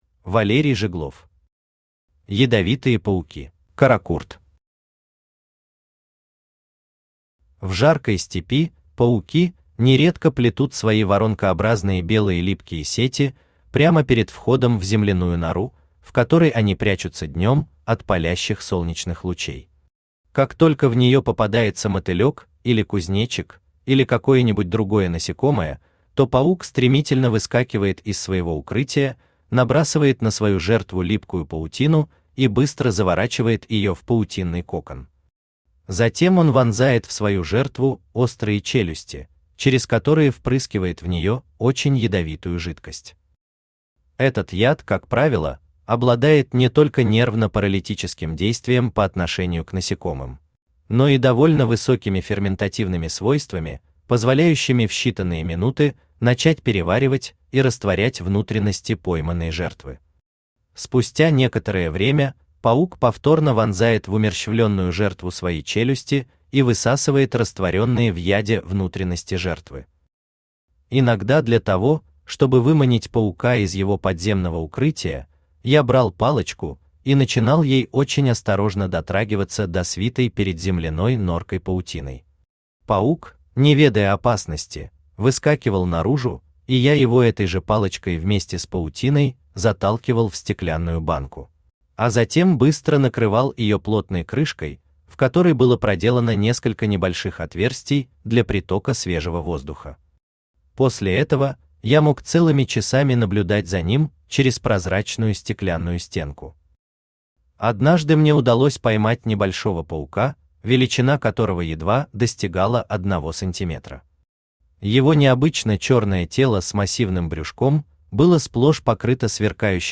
Аудиокнига Ядовитые пауки | Библиотека аудиокниг
Aудиокнига Ядовитые пауки Автор Валерий Жиглов Читает аудиокнигу Авточтец ЛитРес.